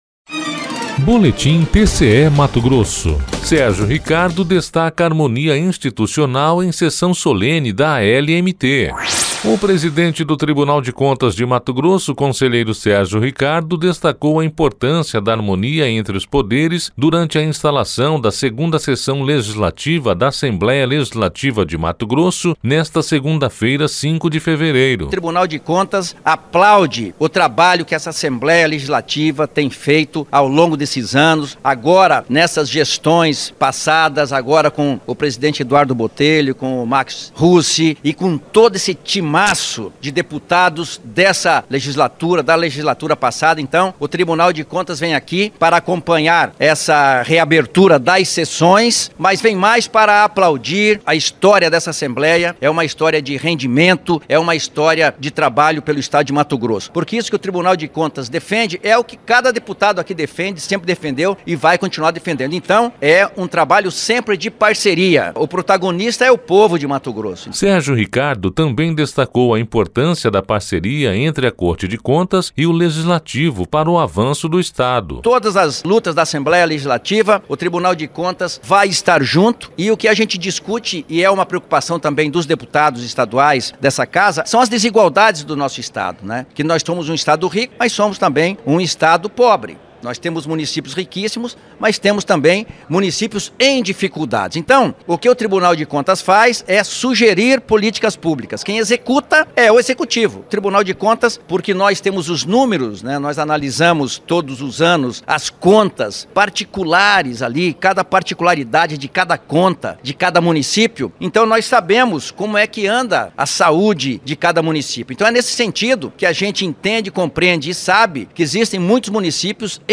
Sonora: Sérgio Ricardo – conselheiro presidente do TCE-MT
Sonora: Eduardo Botelho – deputado presidente da ALMT
Sonora: Mauro Mendes - governador de MT